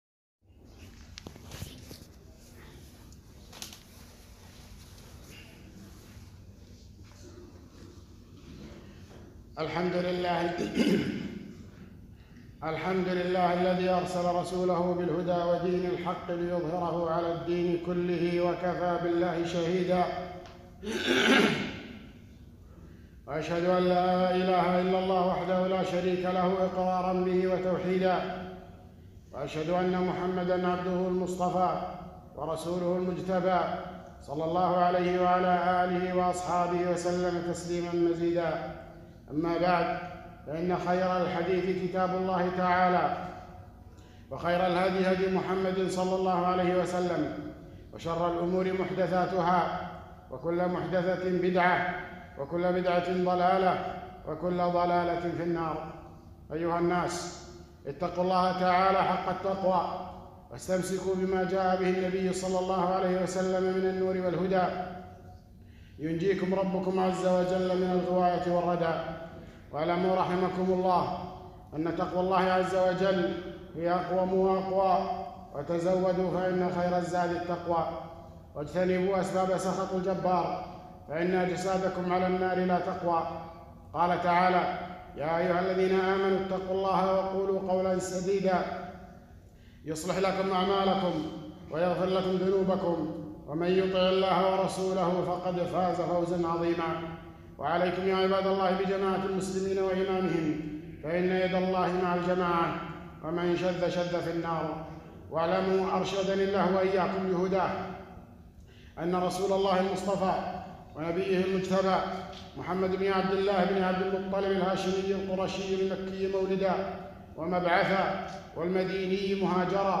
خطبة - محبة المصطفى وبدعة الاحتفال بمولده